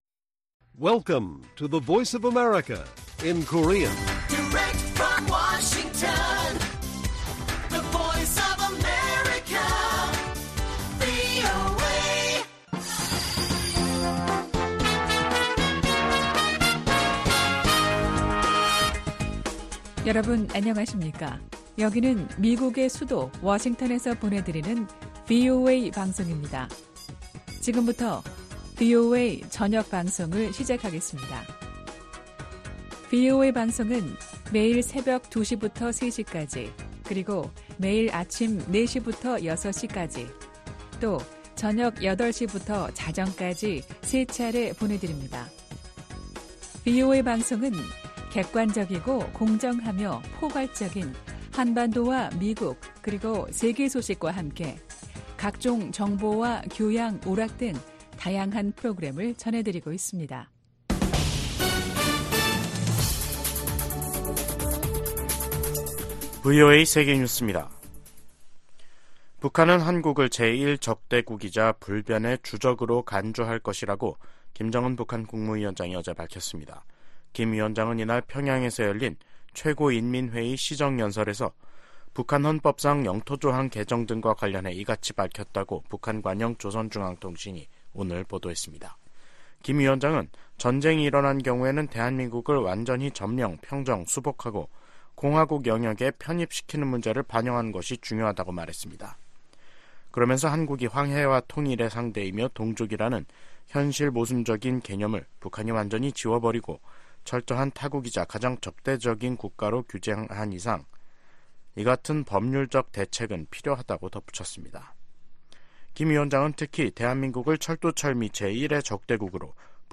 VOA 한국어 간판 뉴스 프로그램 '뉴스 투데이', 2024년 1월 16일 1부 방송입니다. 김정은 북한 국무위원장은 한국을 '제1의 적대국'으로 명기하는 헌법개정 의지를 분명히 했습니다.